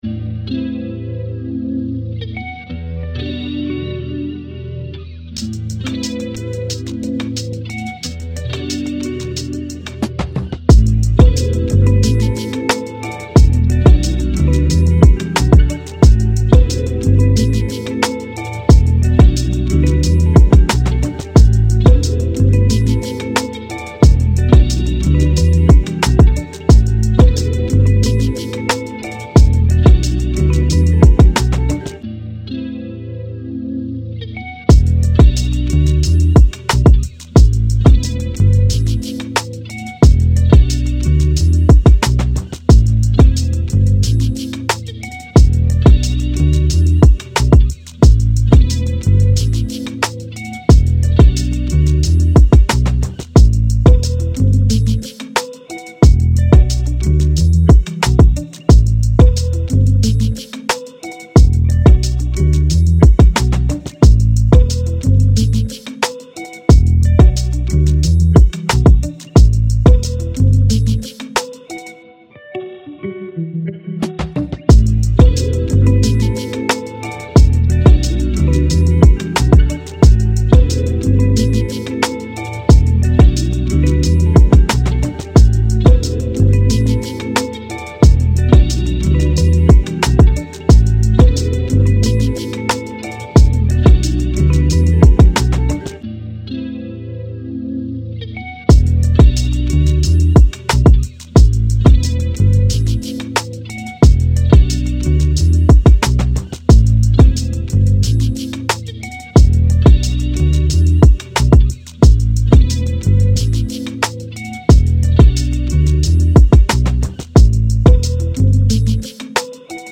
Hip Hop
E Minor